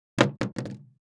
Index of /traerlab/AnalogousNonSpeech/assets/stimuli_demos/jittered_impacts/large_styrofoam_longthin_foamroller